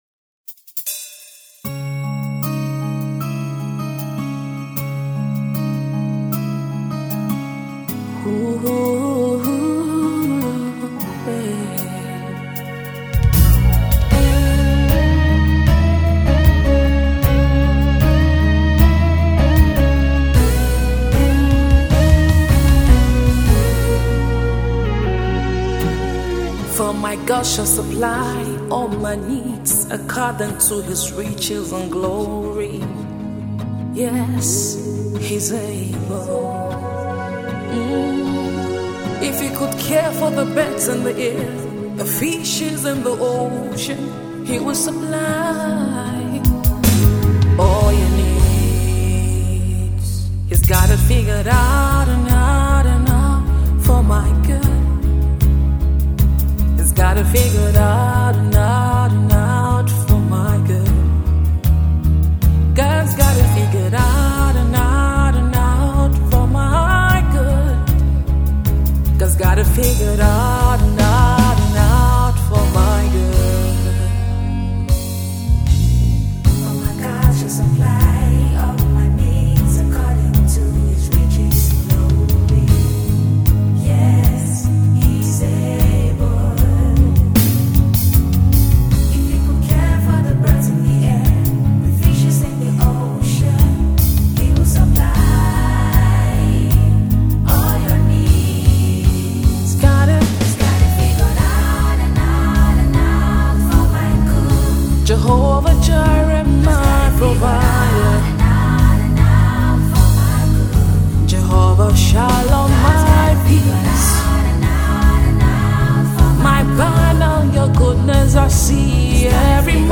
gospel vocalist